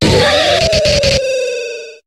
Cri d'Élekable dans Pokémon HOME.